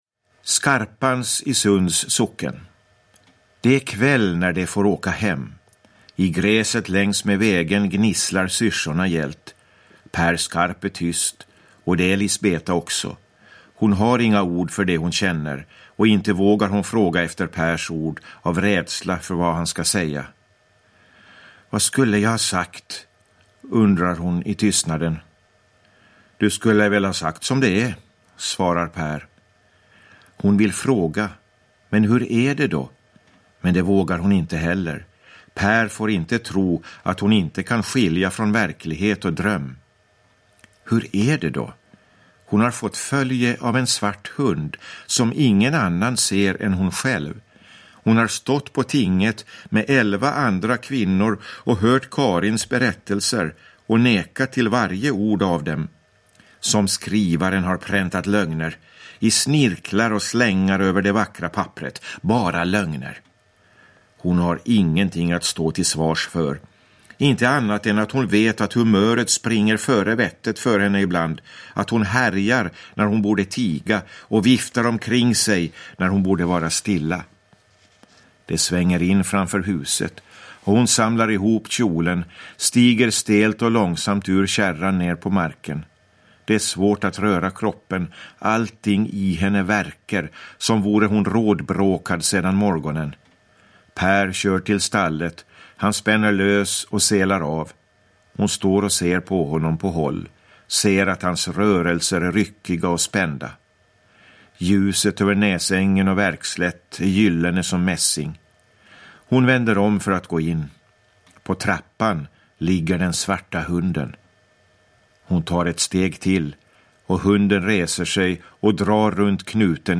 Märket – Ljudbok – Laddas ner